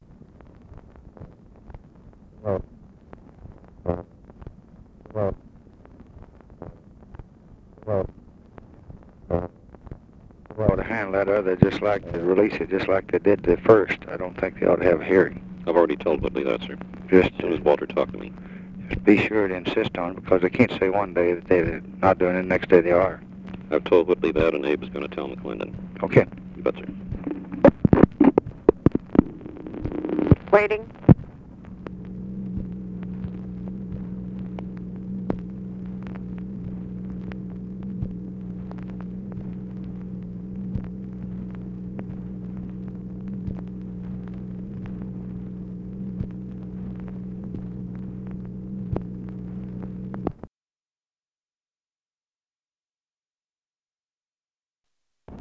Conversation with GEORGE REEDY, January 28, 1964
Secret White House Tapes